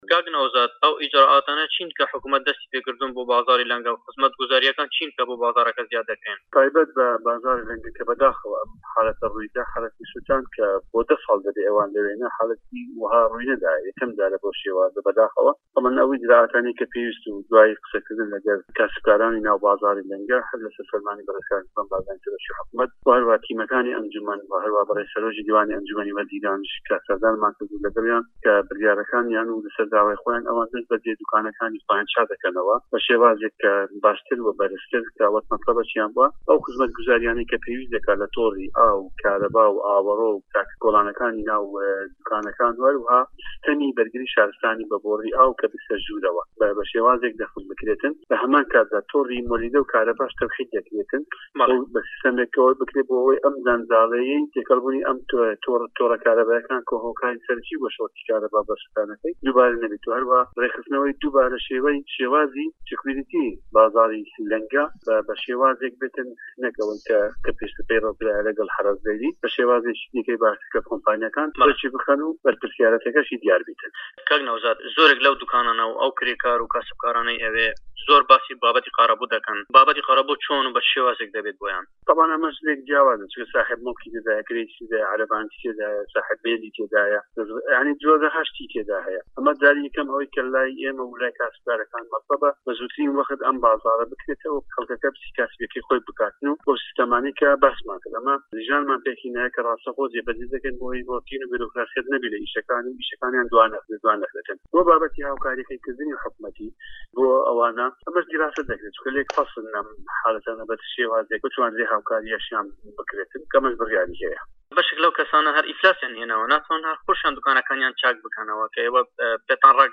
نەوزاد هادی پارێزگاری هەولێر لە بارەی کاسبکارانی بازاڕی لەنگە دەڵێت گرێبەستەکانیان بۆ دە ساڵ درێژکراوەتەوە و ئەوان هەر لەوێ دەبن و دیراسەی ئەوە دەکرێت کە بە چ شێوازێک حکومەت بتوانێت هاوکاریان بکات.